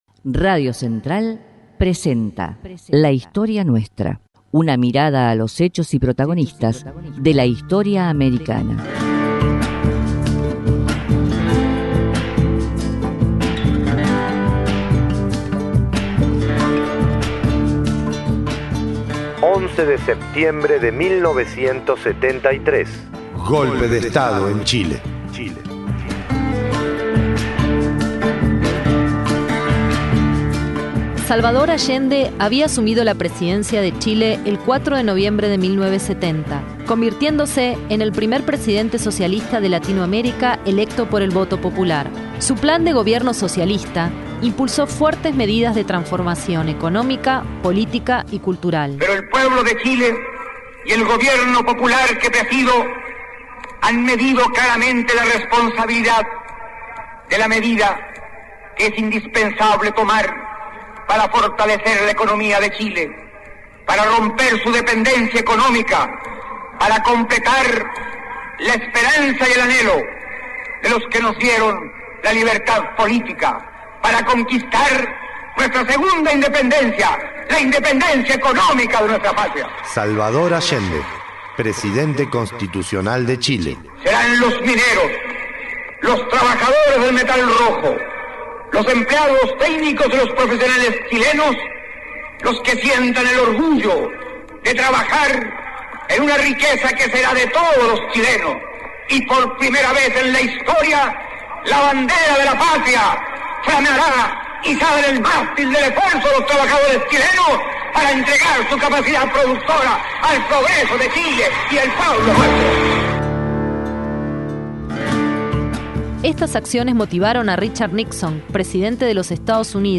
Informe especial sobre el derrocamiento del presidente Salvador Allende